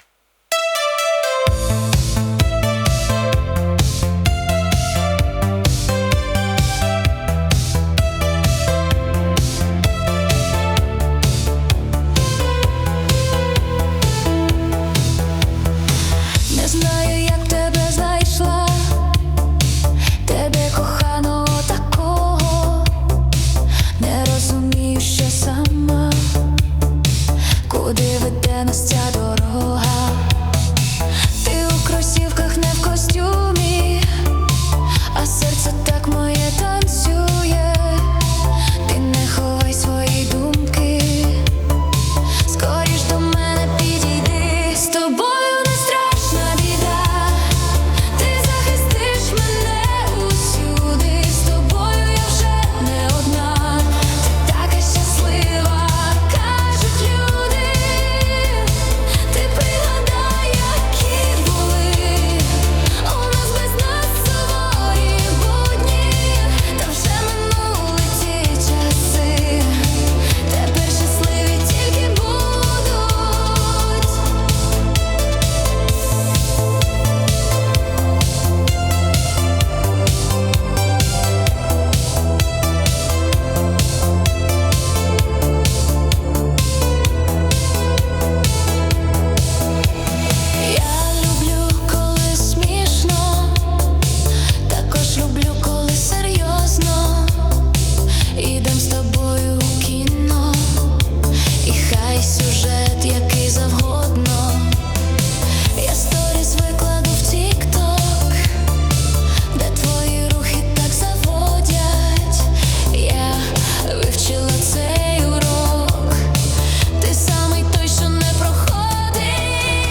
Стиль: Синт-поп